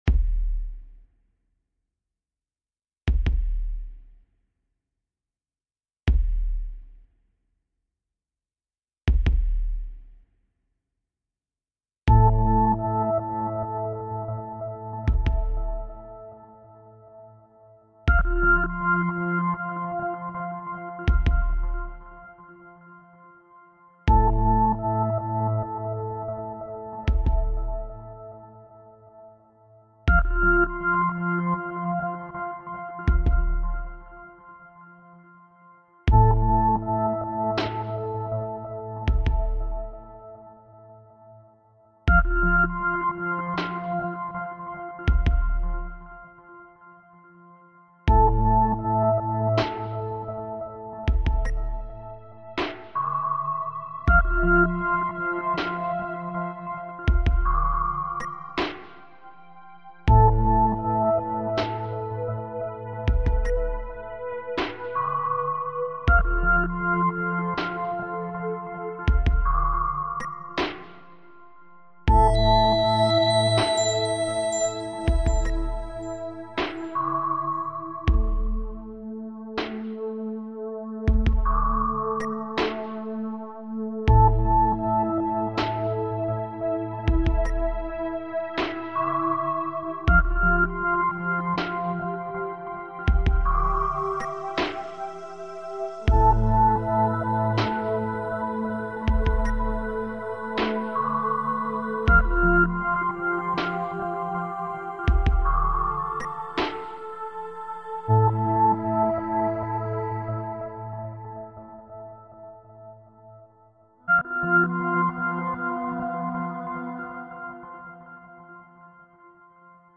это завораживающая композиция в жанре электронной музыки